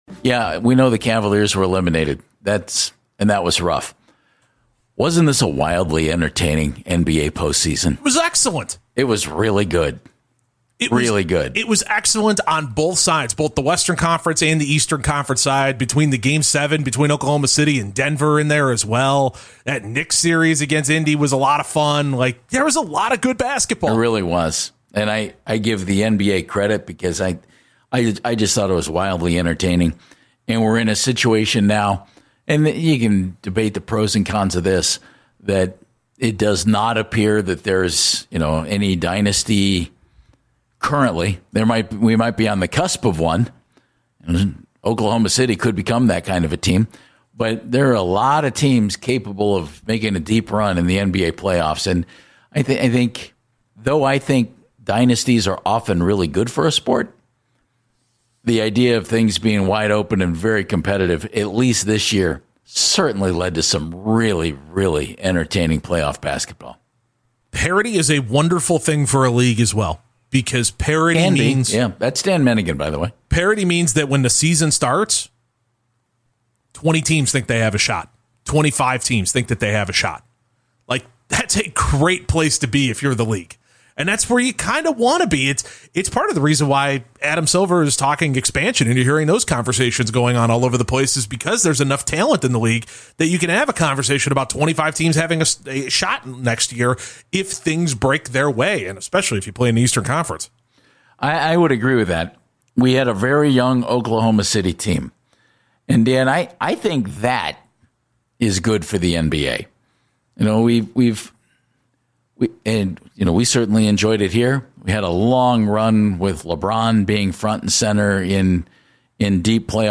The best conversations about the Cavaliers